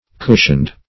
cushioned \cushioned\ (k[oo^]sh"[u^]nd), adj.